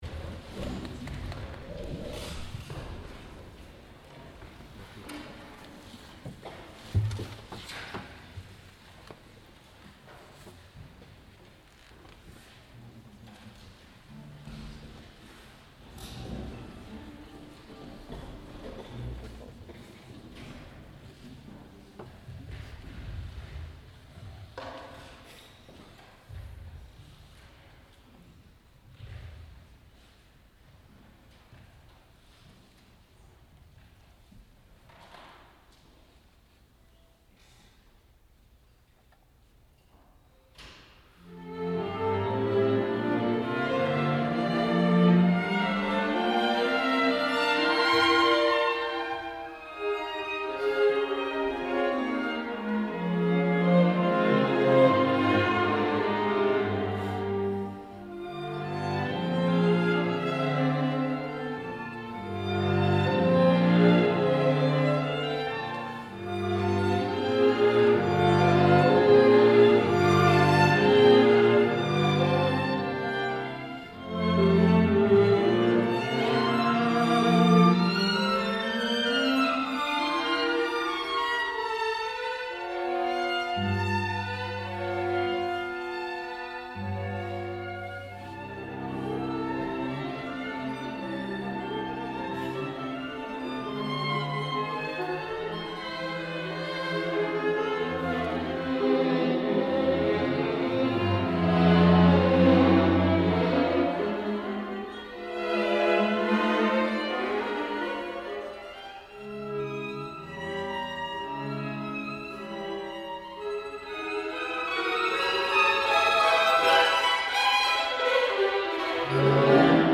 Emerging Artists concert July 9, 2013 | Green Mountain Chamber Music Festival
viola
violin
cello String Quartet in F major
Allegro moderato. Très doux Assez vif. Très rythmé Très lent Vif et agité